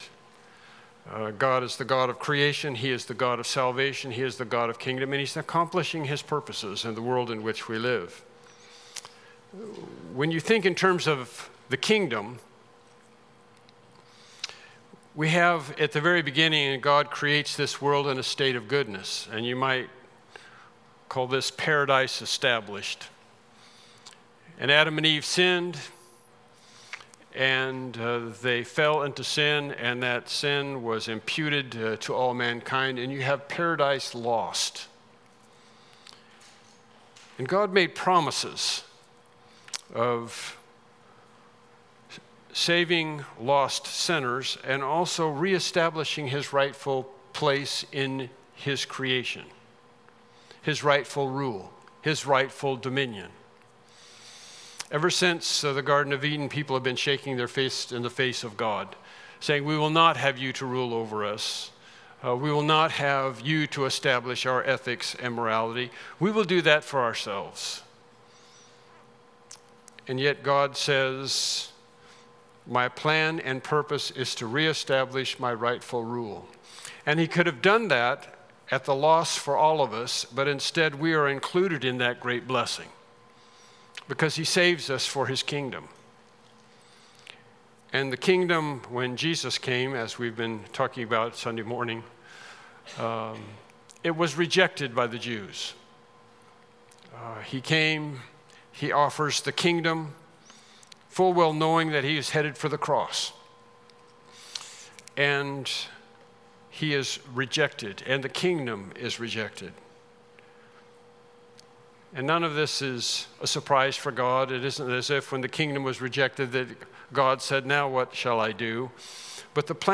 The Study of Things to Come Service Type: Evening Worship Service « Lesson 5